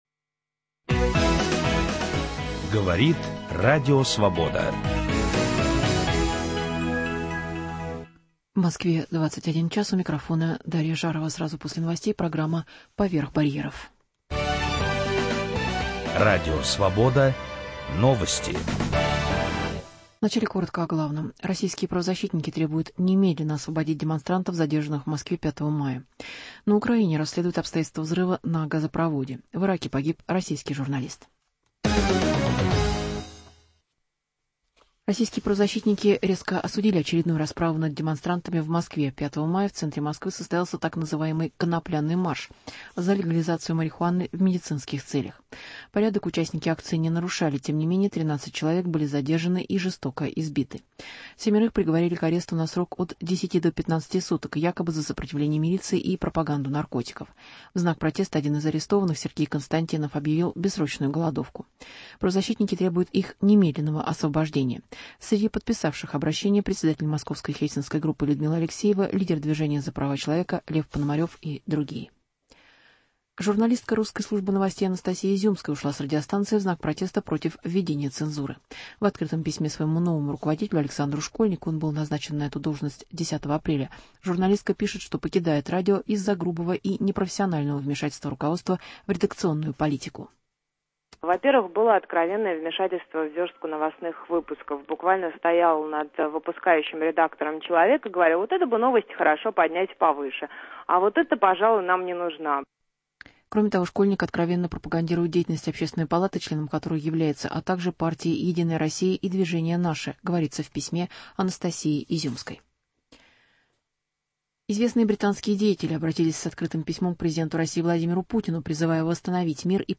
Разговор с Борисом Гребенщиковым - в двух томах, с приложениями и песнями.